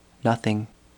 Processing: granulated + KS= 135 (A), F=900, stretch 1:1, then 9:1 on "water" with F=960, DUR/DEL = 30, 50, 50 ms